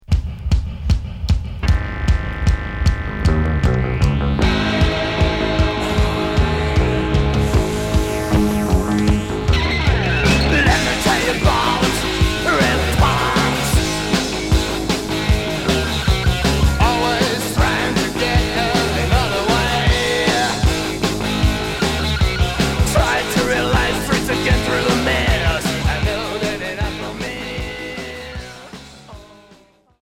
Punk rock Unique 45t retour à l'accueil